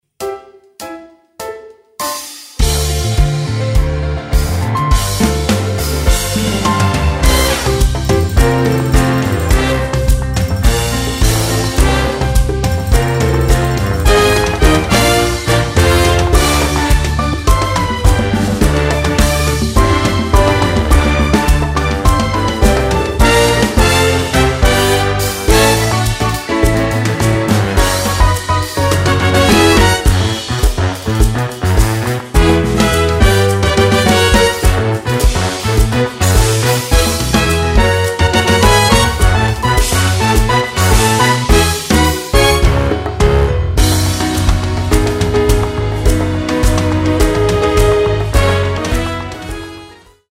performance track
Instrumental
orchestral , backing track